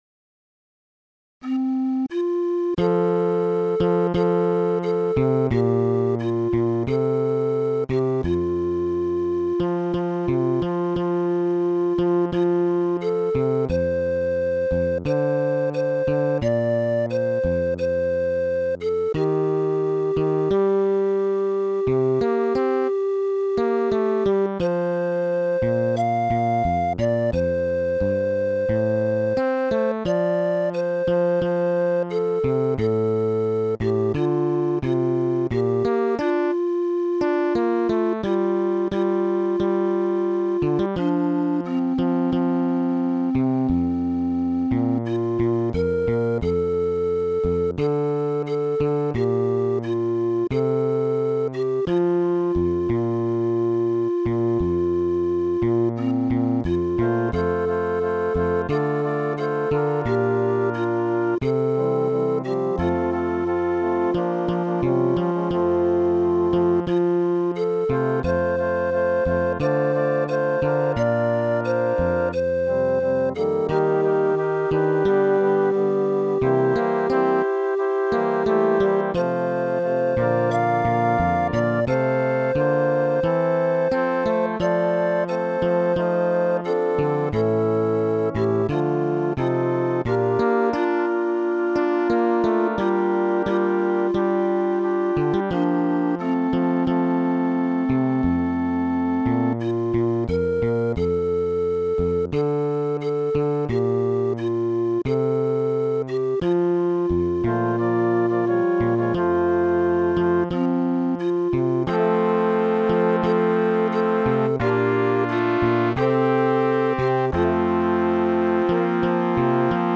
SAATBB + SATB + MzS (10 voices mixed) ; Full score.
Genre-Style-Form: Sacred Mood of the piece: impressive ; descriptive ; contemplative
Soloist(s): Mezzo-Sopran (1 soloist(s))
Tonality: F major